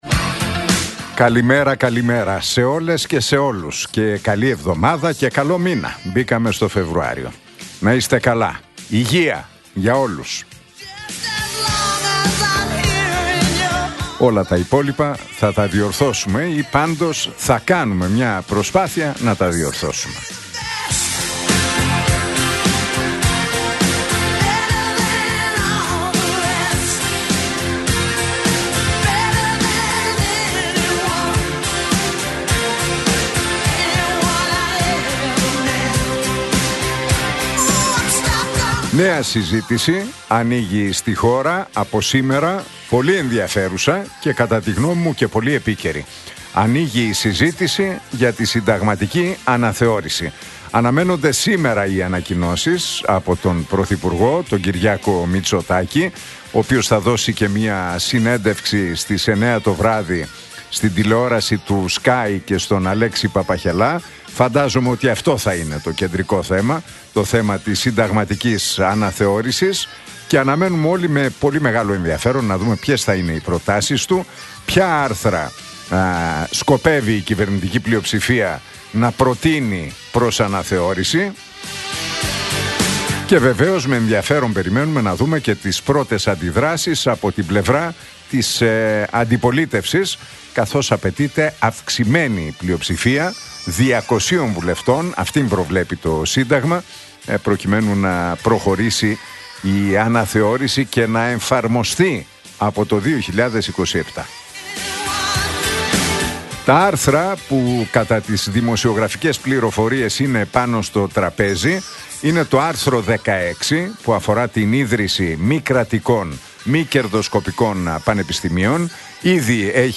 Ακούστε το σχόλιο του Νίκου Χατζηνικολάου στον ραδιοφωνικό σταθμό Realfm 97,8, τη Δευτέρα 2 Φεβρουαρίου 2026.